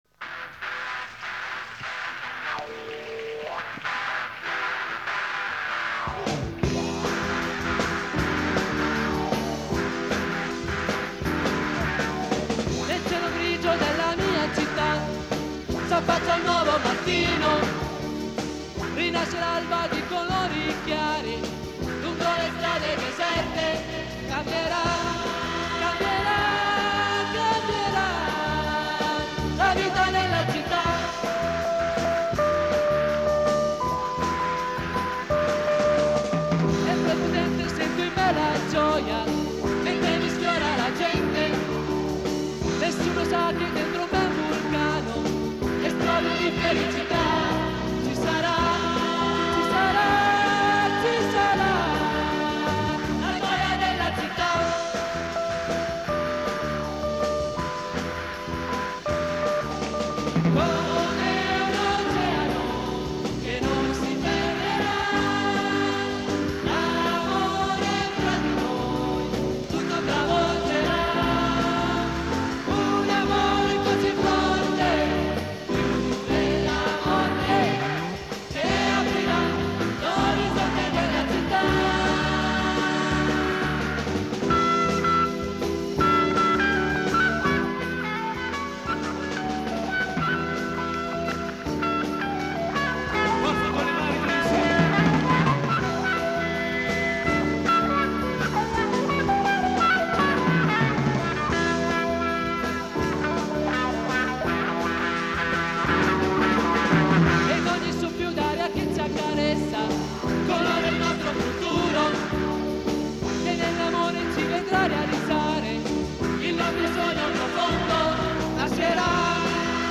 Download   CD live (1983-1993) del 1998